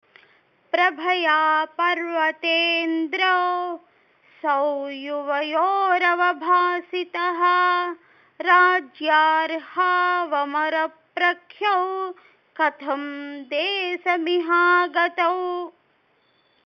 Audio Recitation